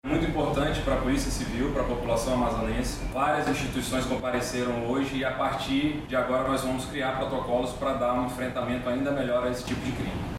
Durante a reunião, o delegado-geral da Polícia Civil do Amazonas, Bruno Fraga, destacou a importância de se discutir o tema e garantiu melhorias nas abordagens policiais.
SONORA-1-REUNIAO-VIOLENCIA-OBSTETRICA-.mp3